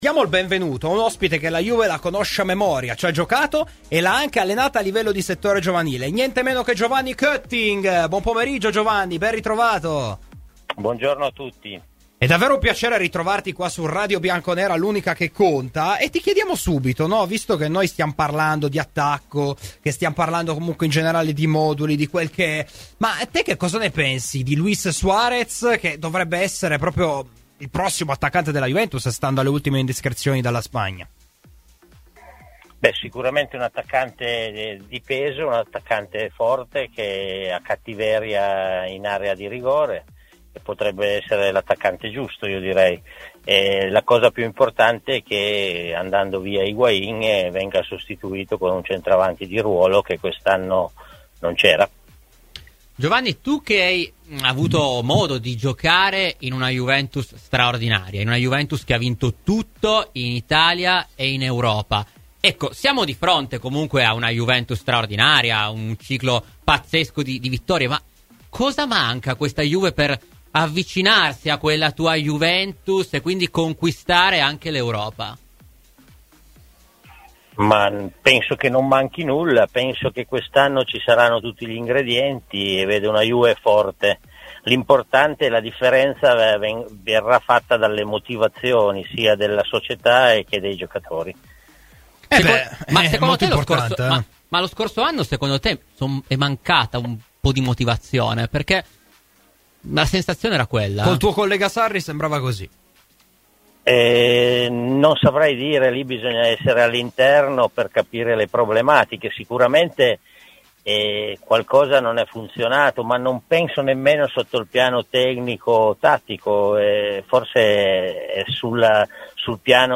Intervistato da Radio Bianconera nel corso di ‘Domenica Sport’